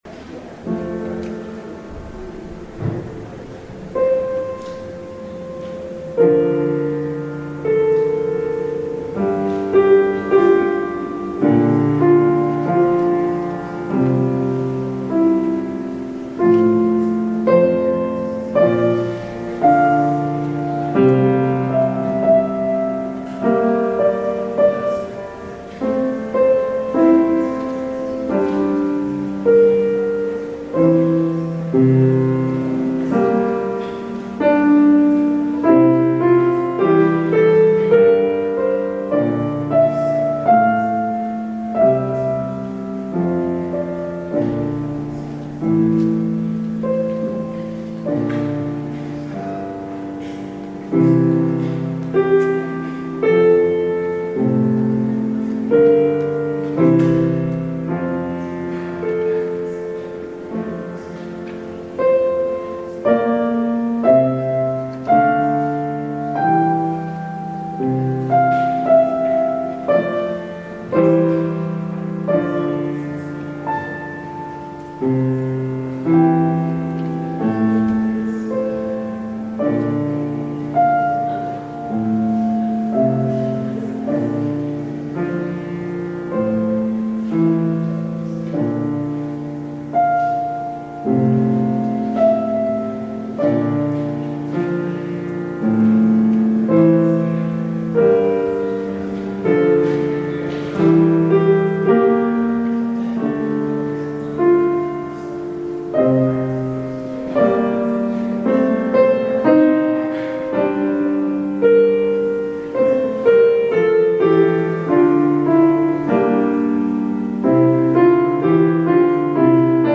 Audio recording of the 10am hybrid/streamed service
Communion music